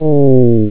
sfx_damage.wav